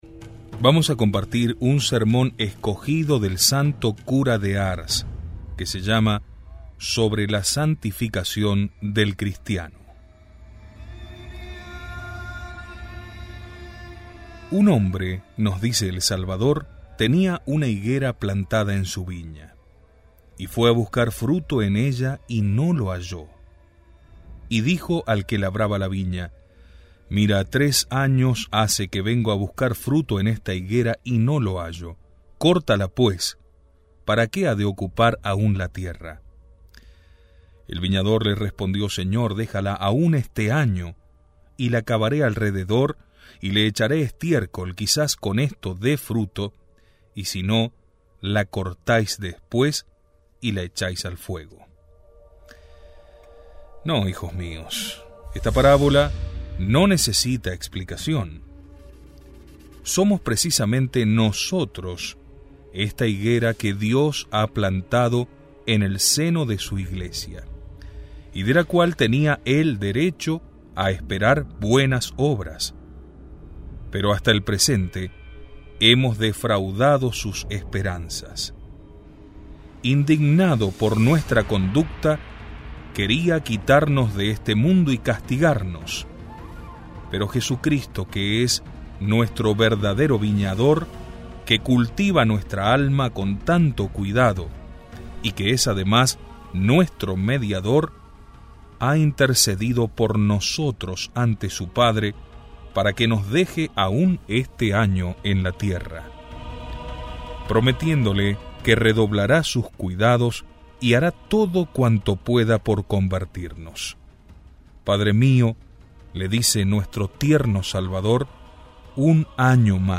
Audio–libro